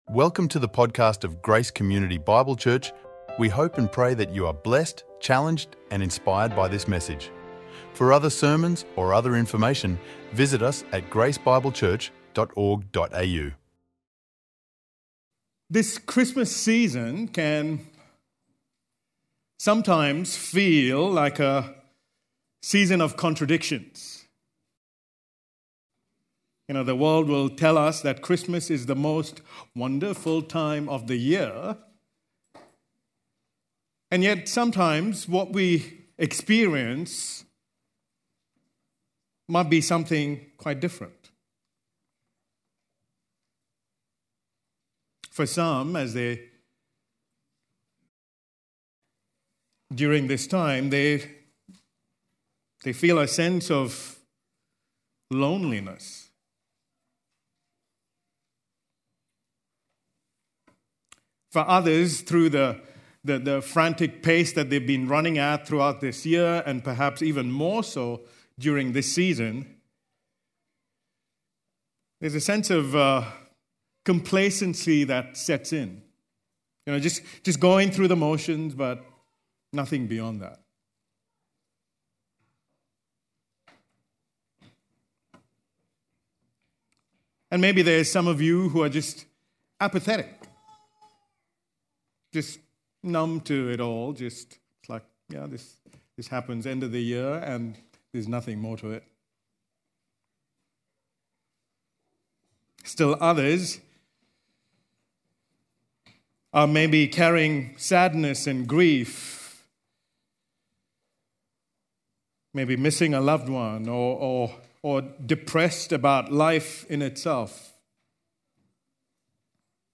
recorded live at Grace Community Bible Church